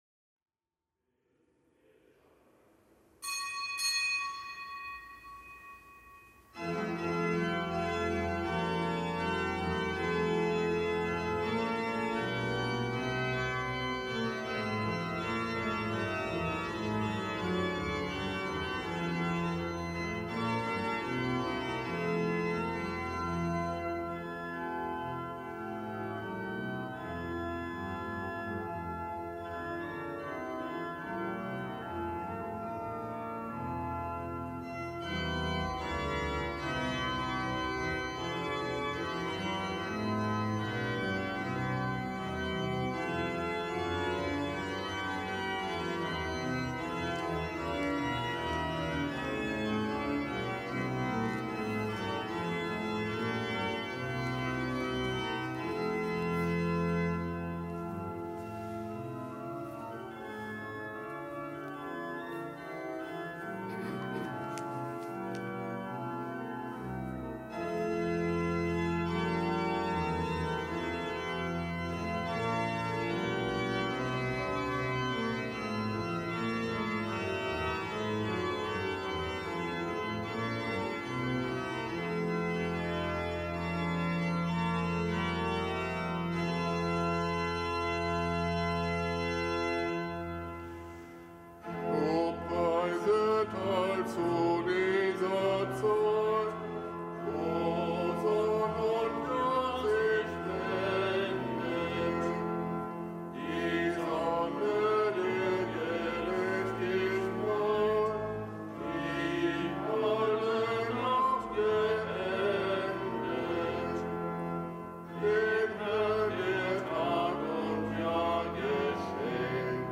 Kapitelsmesse am Gedenktag von Basilius dem Großen
Kapitelsmesse aus dem Kölner Dom am Gedenktag des Heiligen Basilius dem Großen und des Heiligen Gregor von Nazianz.